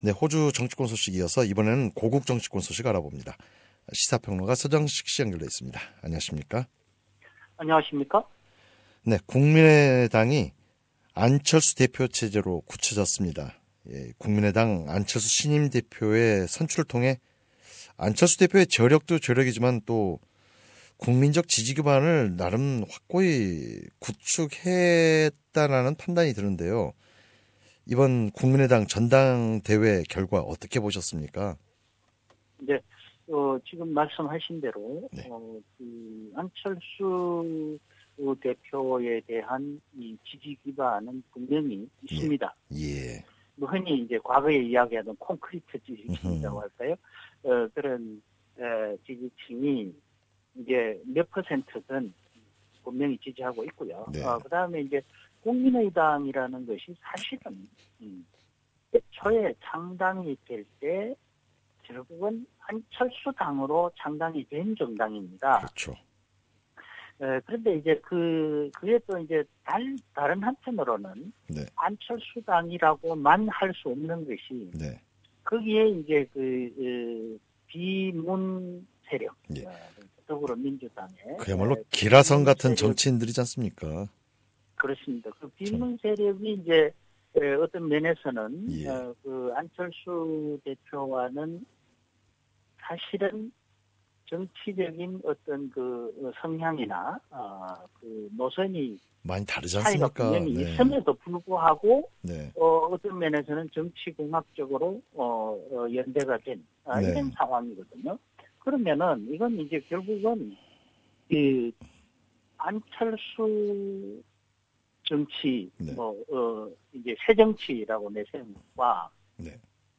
Political commentator